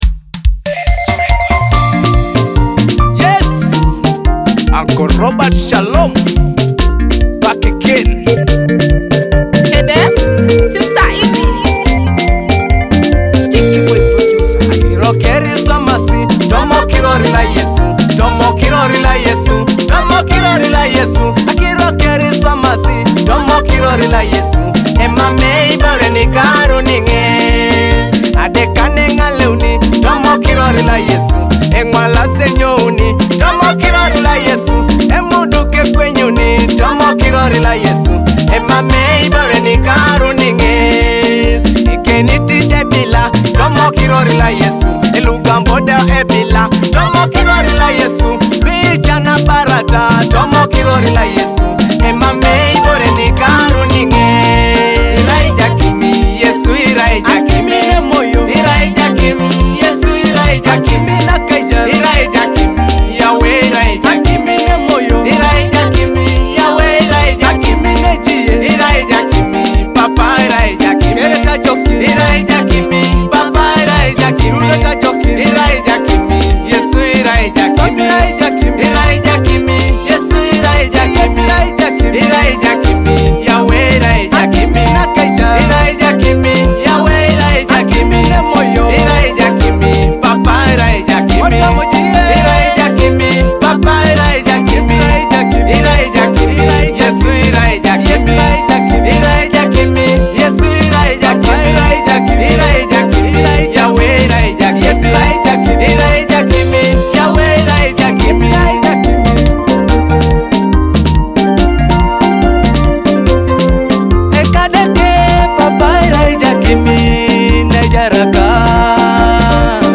a powerful gospel praise song.